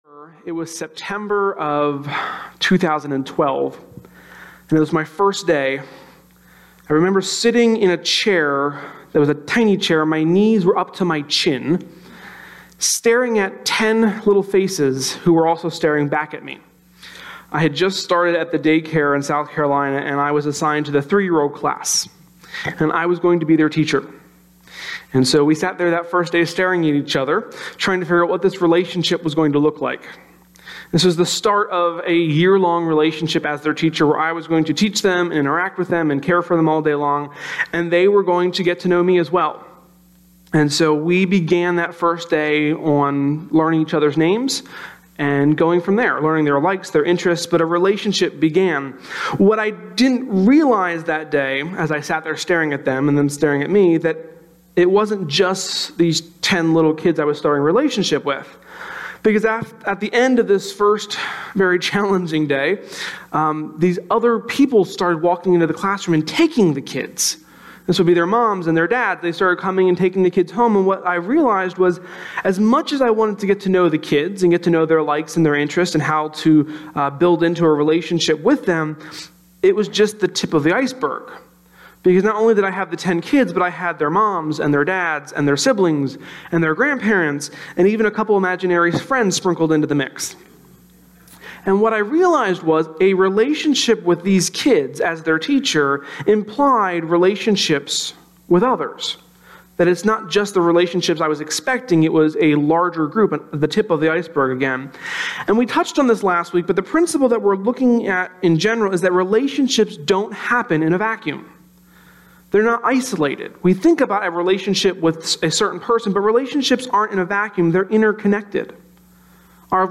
sermon-2.4.18.mp3